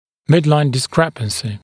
[‘mɪdlaɪn dɪs’krepənsɪ][‘мидлайн дис’крэпэнси]несоответствие средней линии (-ий)